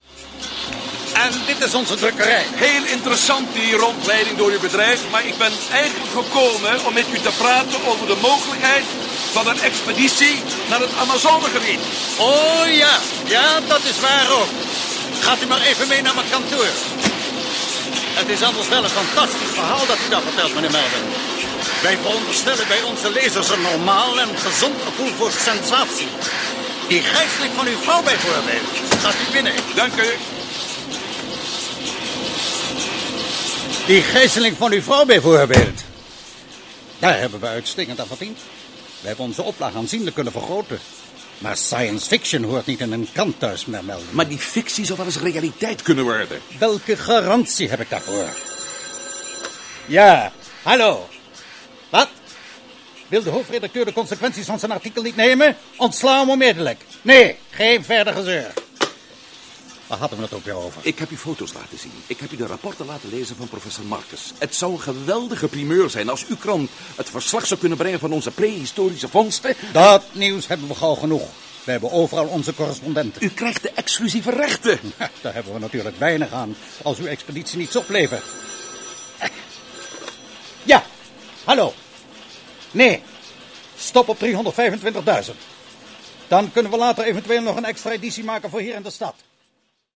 Rol(len) in de hoorspelreeks: